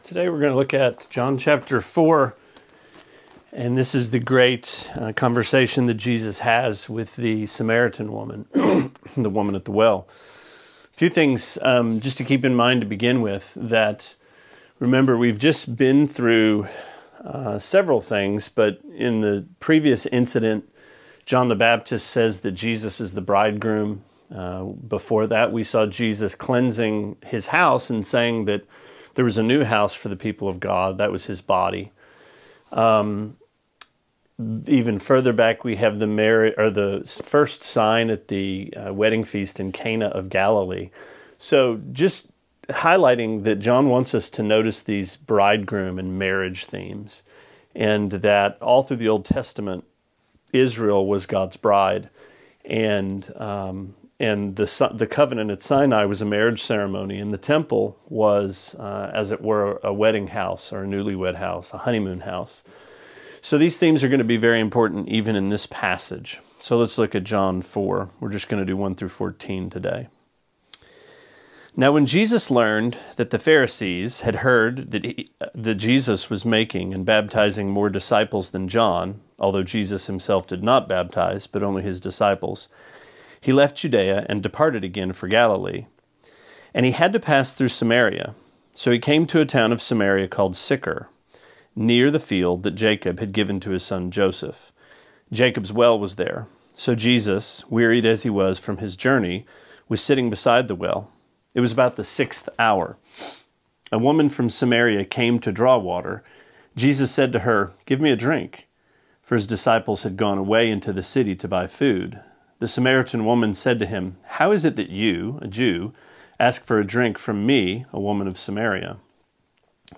Sermonette 4/23: John 4:1-14: Women at Wells